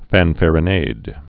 (fănfăr-ə-nād, -näd)